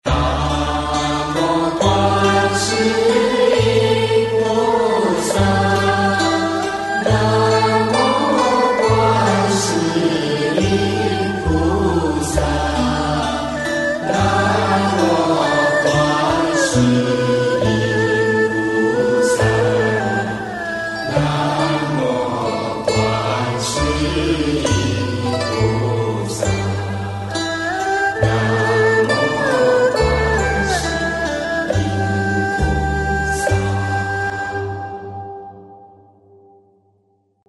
Traditional Guan Yin Chant - Audio